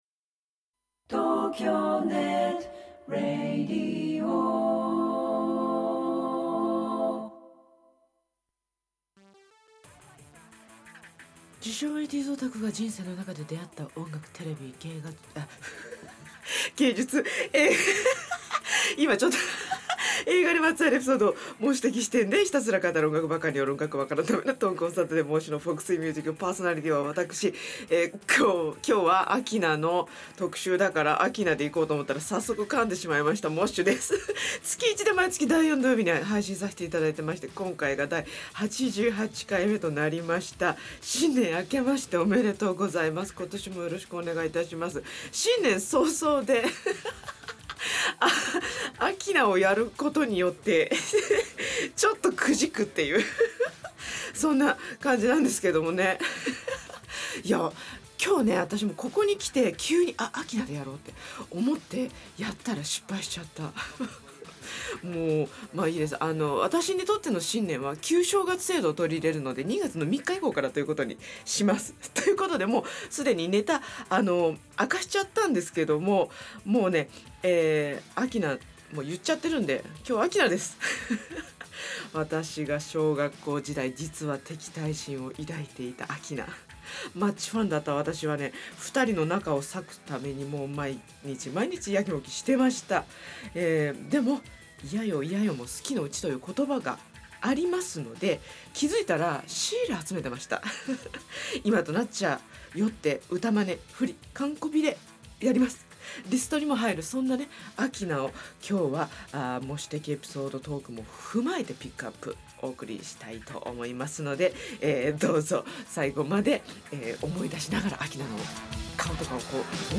冒頭から急に明菜の声マネやろうと 思い立つも見事に着地失敗(笑)